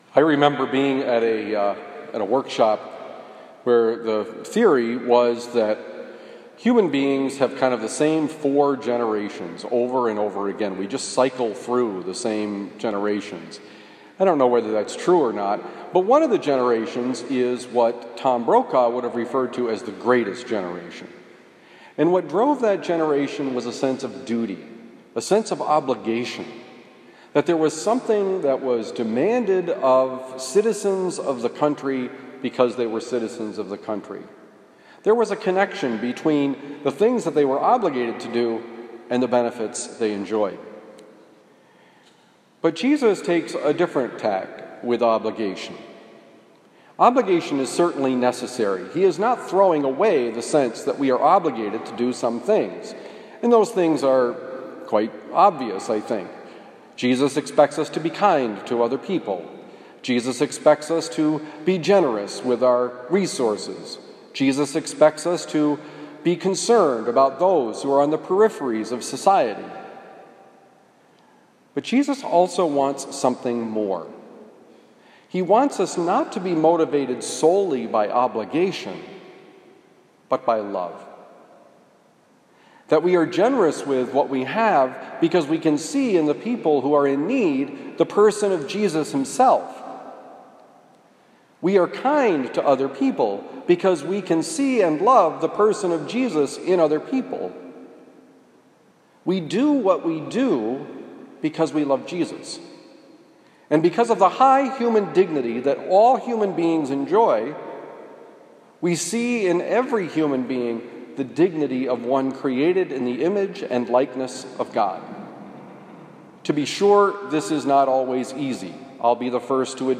Homily for November 10, 2020 – The Friar